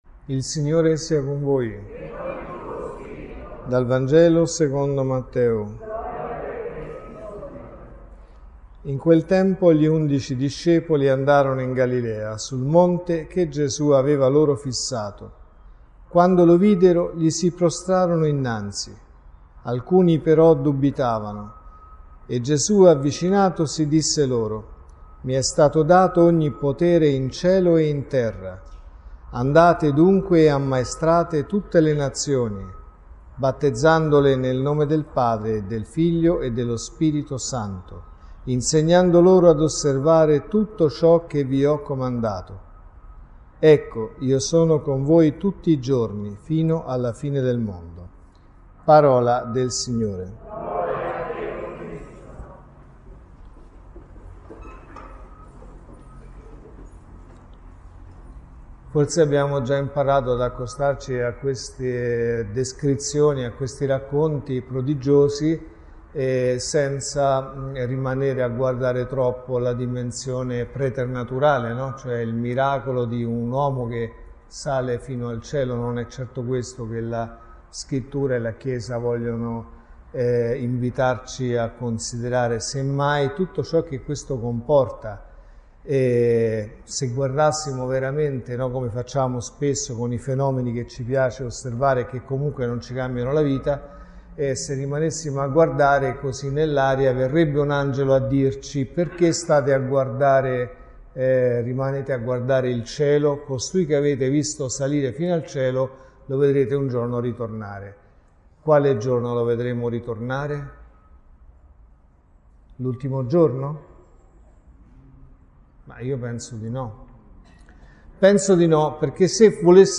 A me è stato dato ogni potere in cielo e sulla terra (Messa del mattino e della sera)